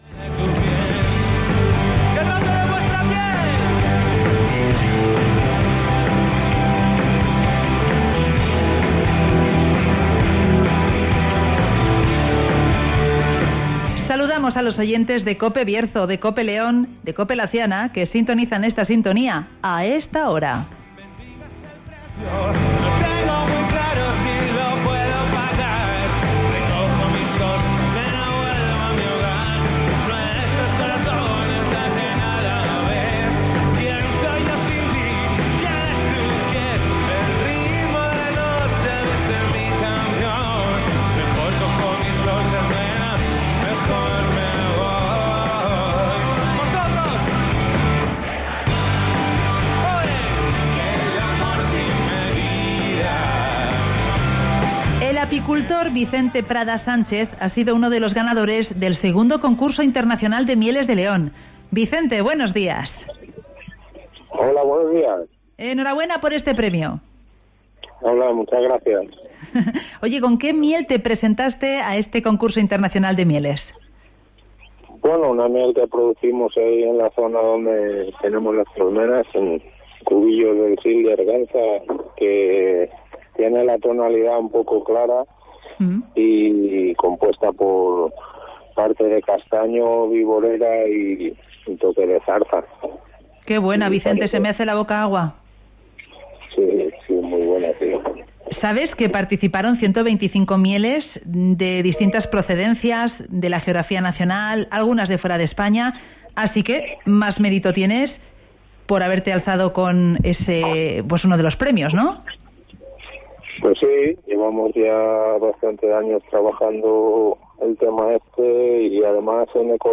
Dos mieles bercianas ganan la Cata Final del II Concurso Internacional de León (Entrevista